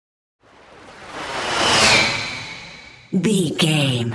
Whoosh bright
Sound Effects
Atonal
bright
futuristic
tension
whoosh